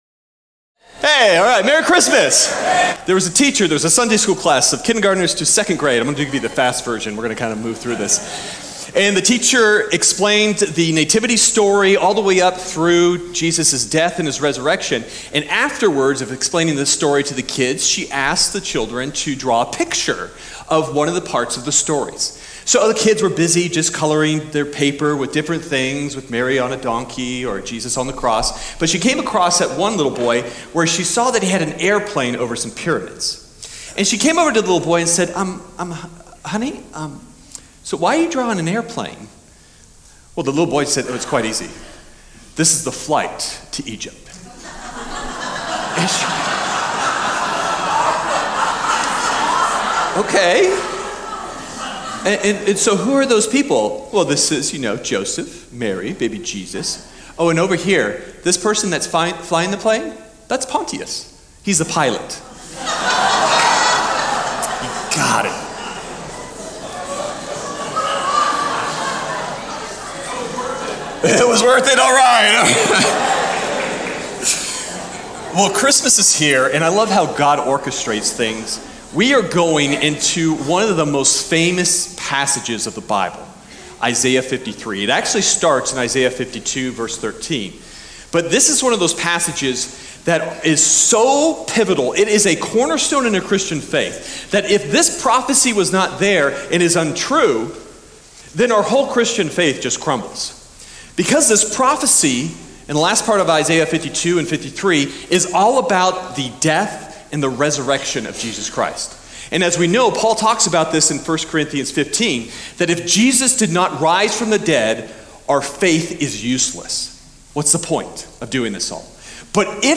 Sermon Detail
12_03_Sermon_Audio.mp3